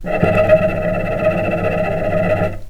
vc_trm-E5-pp.aif